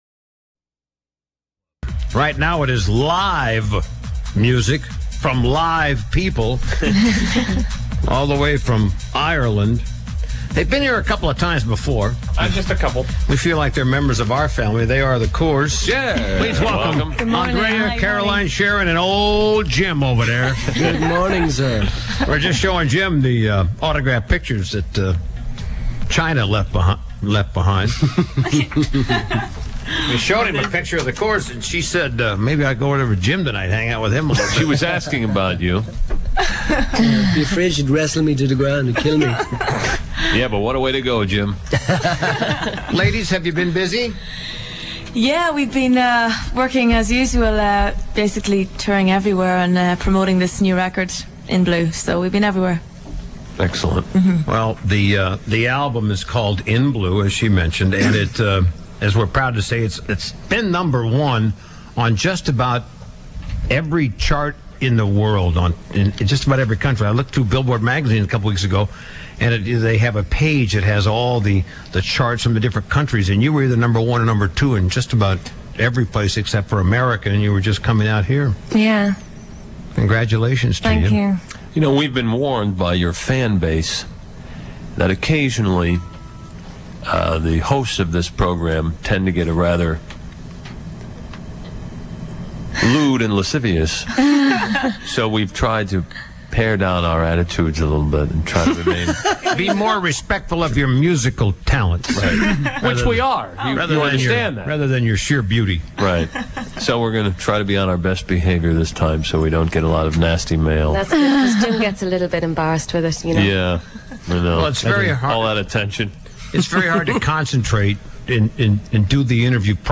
WPLJ Interview (audio)
WPLJ Interview-Intro